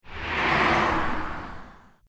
A Car Whizzing By 04
a_car_whizzing_by_04.mp3